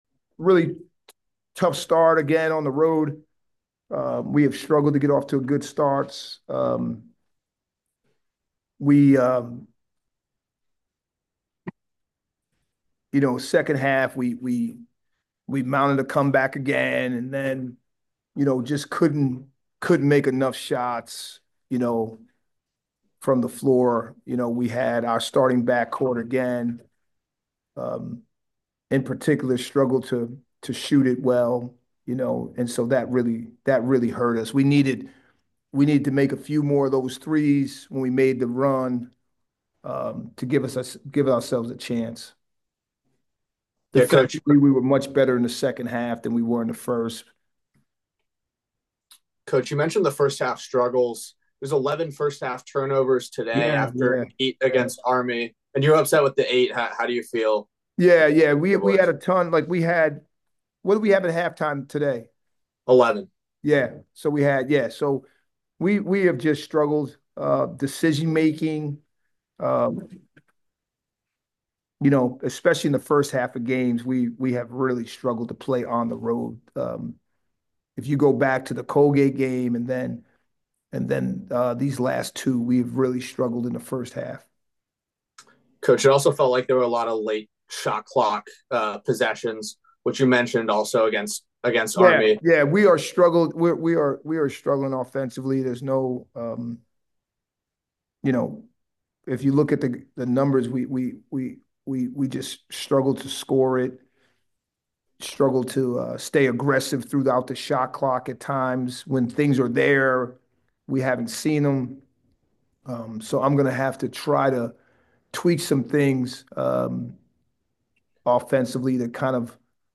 Men's Basketball / Navy Postgame Interview (1-22-25)